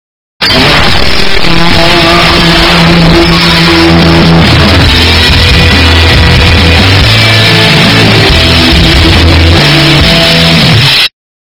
Categoria: Sons virais
Descrição: O grito épico de vitória "BOOYAH" do Free Fire, na versão completa e estourada! Com som turbinado, bass reforçado e pronto pra bombar, esse áudio é perfeito pra zoar nos grupos do WhatsApp, criar memes no TikTok ou dar aquele clima de campeão.